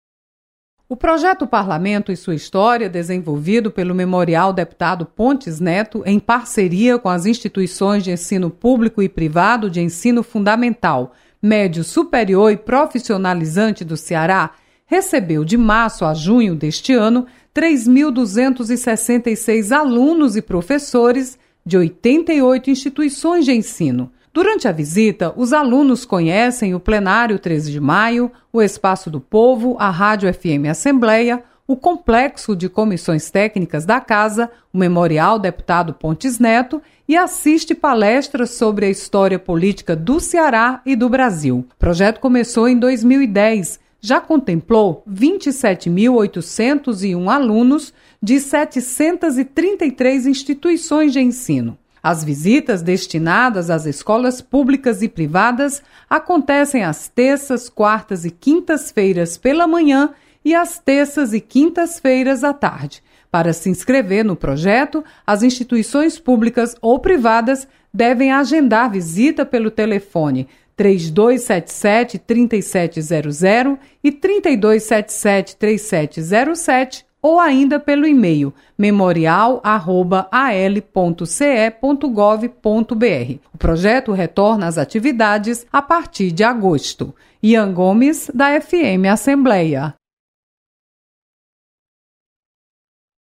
Memorial Pontes Neto faz balanço das atividades no primeiro semestre de 2018. Repórter